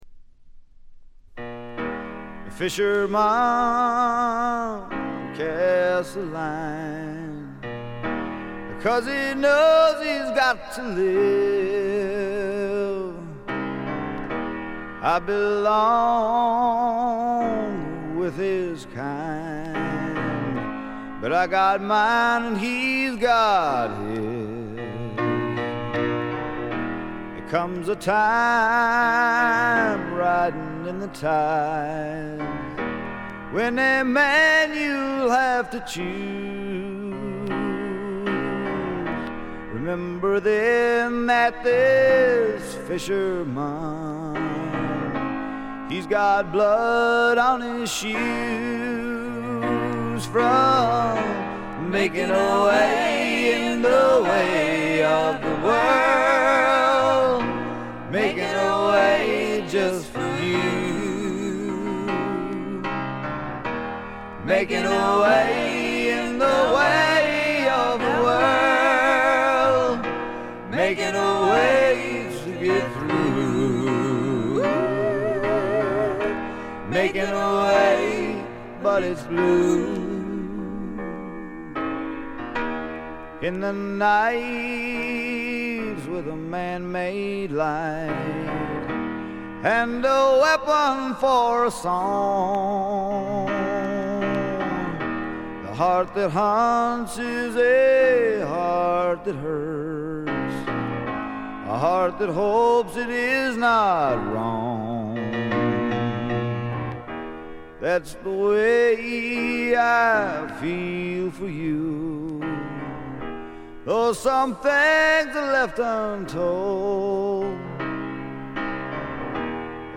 軽微なバックグラウンドノイズ、チリプチ程度。
聴くものの心をわしづかみにするような渋みのある深いヴォーカルは一度聴いたら忘れられません。
試聴曲は現品からの取り込み音源です。